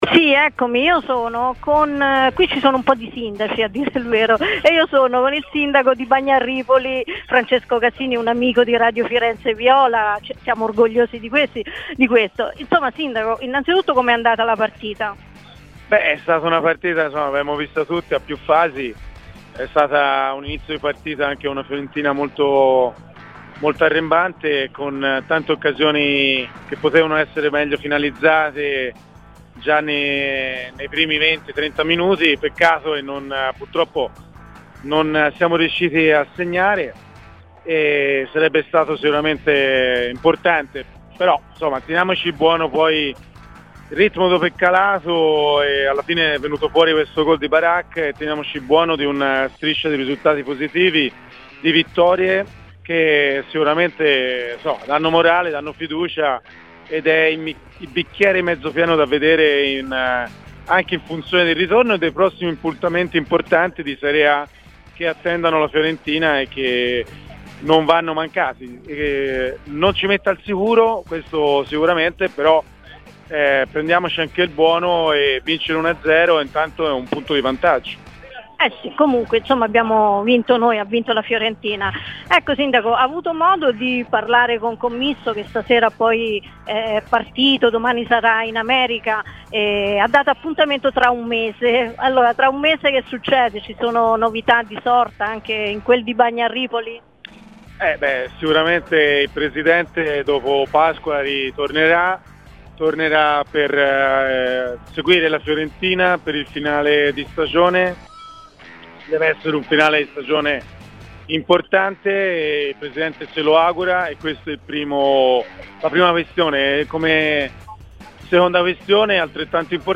Il sindaco di Bagno a Ripoli Francesco Casini, presente ieri allo stadio per la partita Fiorentina-Sivasspor, nel dopo gara ha parlato a Radio Firenzeviola anche del suo incontro con il presidente Commisso e del Viola Park: "Il presidente dopo Pasqua ritornerà per seguire la Fiorentina nel finale di stagione che lui si augura sia importante, questa è la prima missione.